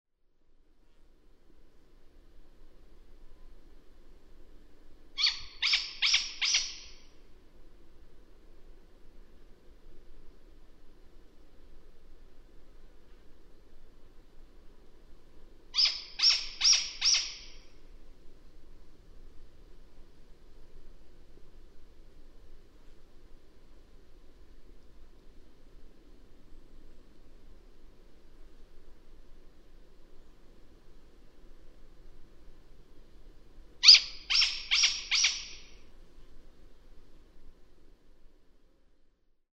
A szakállas bagoly (Strix nebulosa) hangja
A szakállas bagoly hangja leginkább egy mély, rezonáló huhogás, ami néha szinte „kísértetiesnek” hathat az éjszakai erdőben.
• Mély hangfekvés: A szakállas bagoly hangja a többi bagolyfajhoz képest mélyebb és rezonálóbb.
Mély, rezonáló huhogása messzire elhallatszik az erdőben.
szakallas-bagoly-hangja.mp3